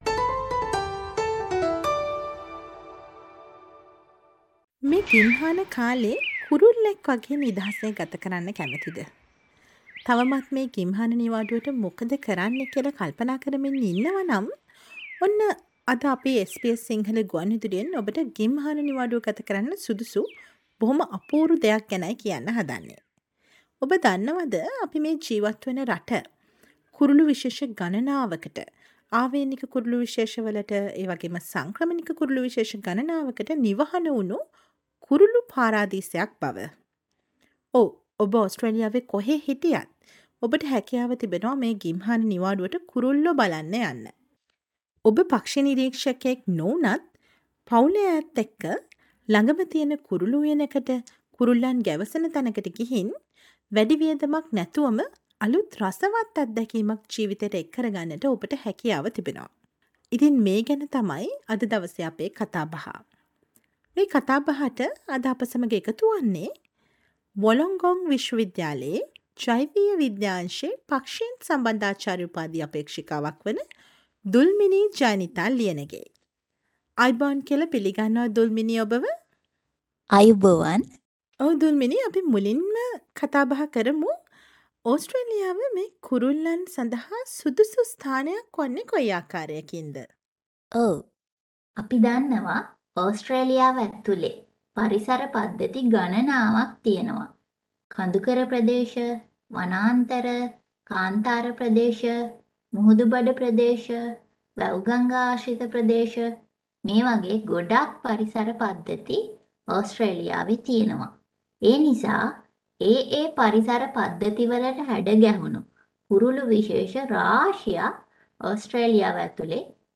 Listen to the SBS Sinhala interview on attractive and enchanting world of birds in Australia for you to peek in this summer holidays.